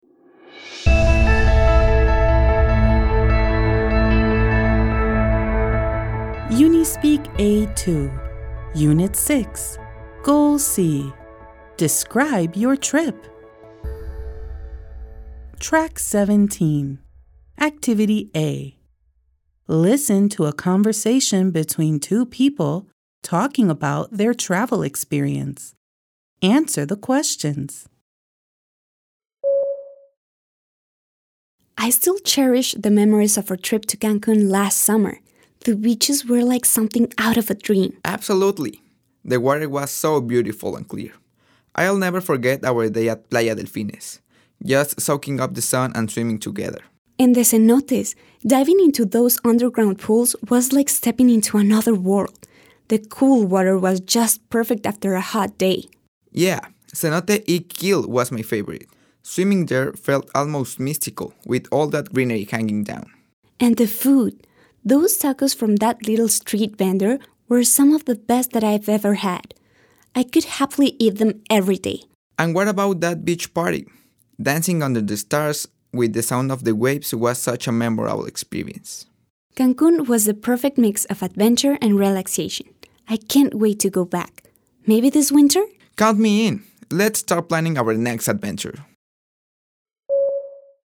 Goal C. Describe Your Trip. Activity A. Listen to a conversation between two people talking about their travel experience.